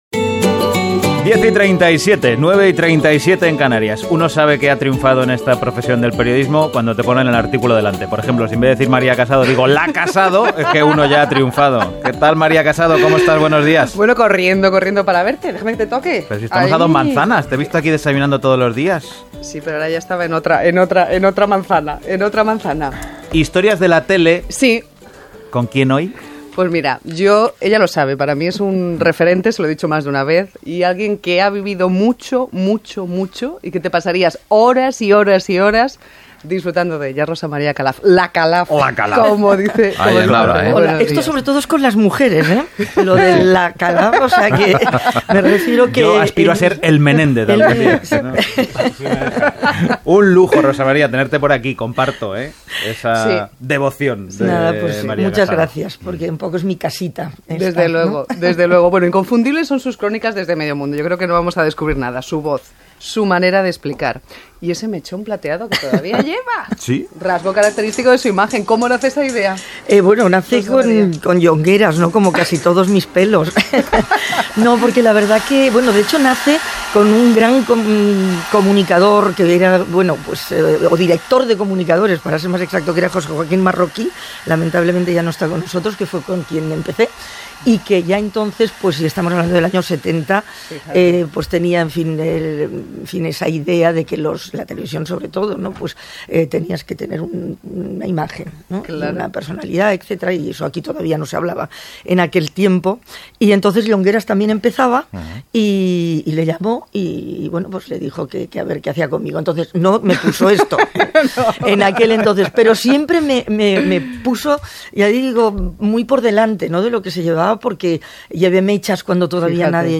Las Mañanas de RNE: entrevista